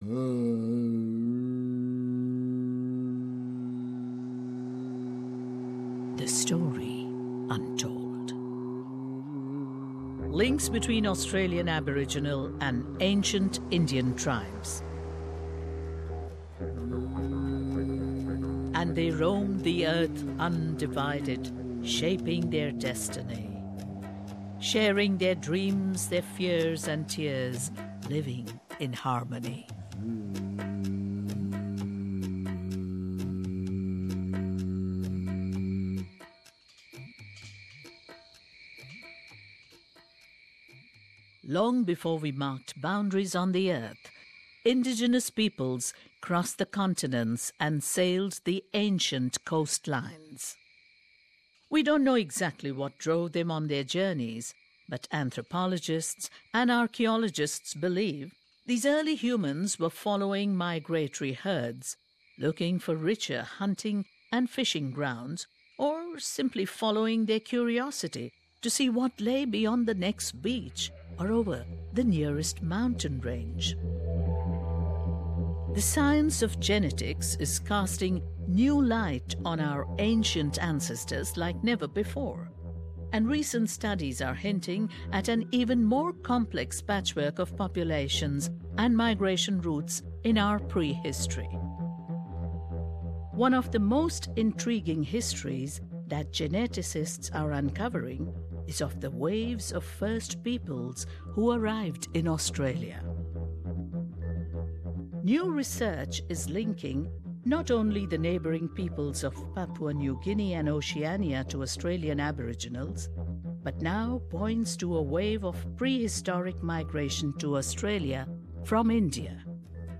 In Part One of this two-part radio special, originally published in 2014